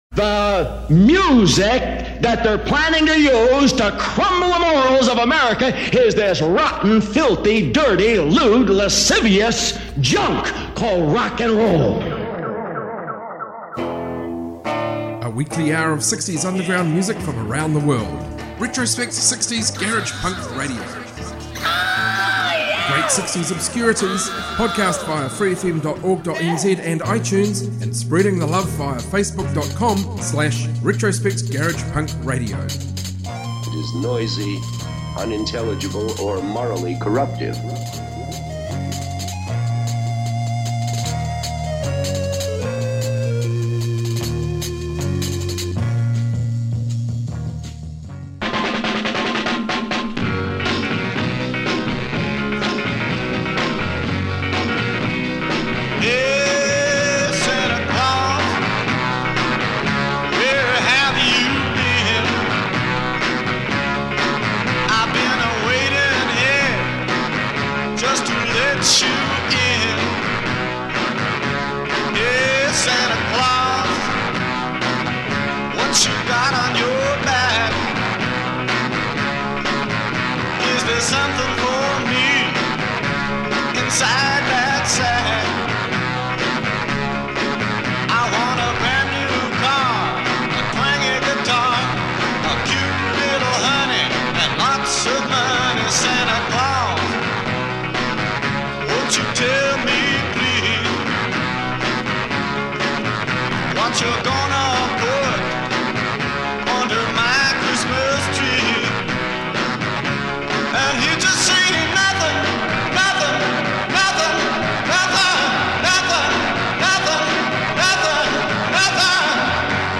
Garage rock, garage punk, proto-punk, freakbeat, psychedelia